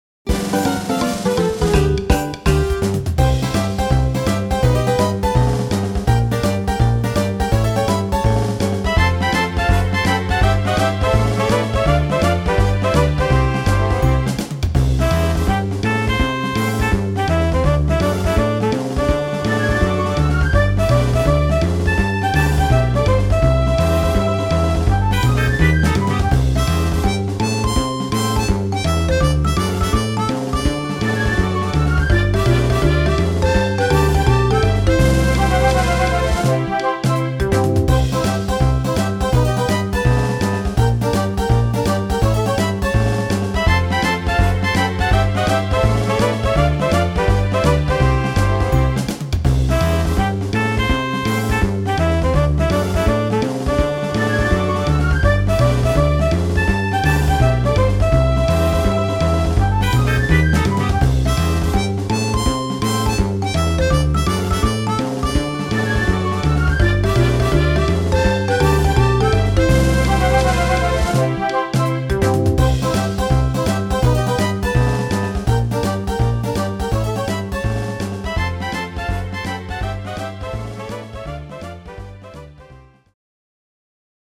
原曲よりややゆっくりめ。